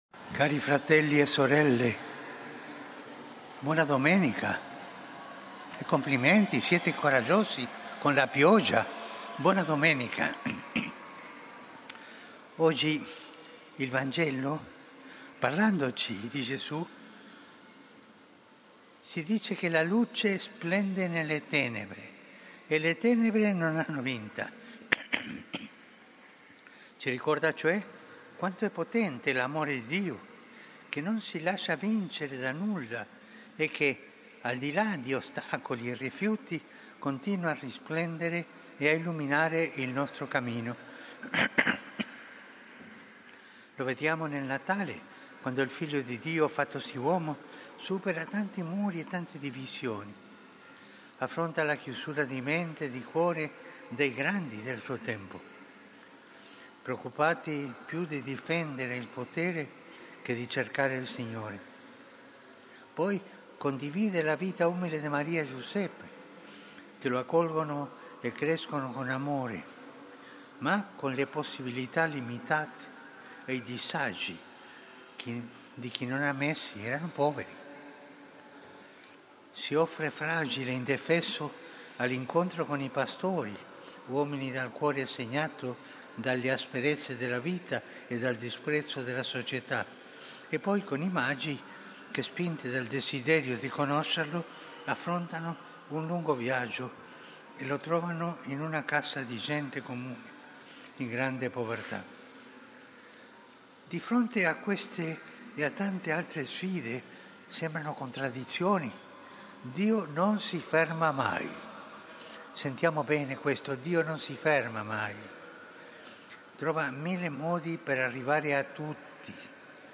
ANGELUS
Piazza San Pietro